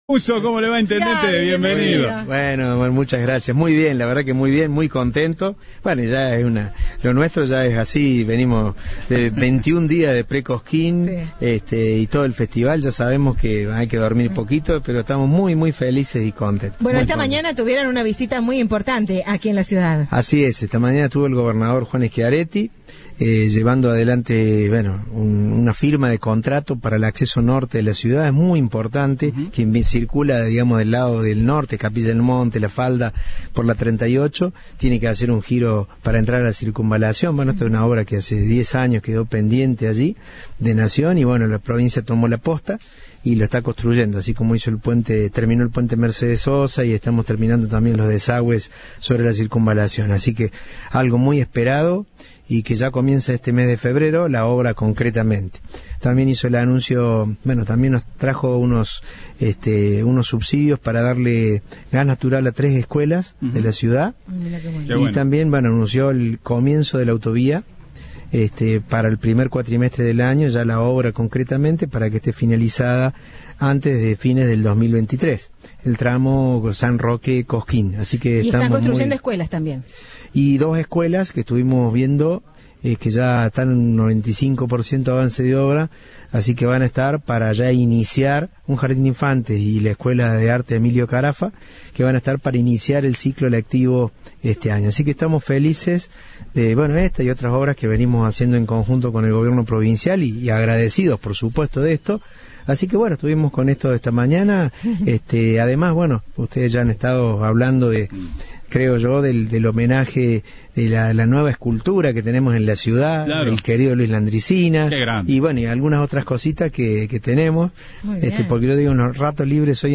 El intendente de la localidad dialogó con Cadena 3 sobre el evento y remarcó la importancia de que se genere trabajo a través del mismo. Aseguró que se cumplen todos los protocolos sanitarios.